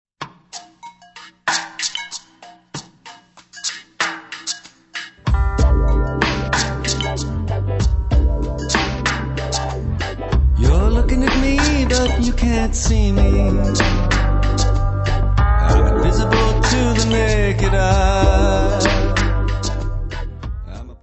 : stereo; 12 cm
Área:  Pop / Rock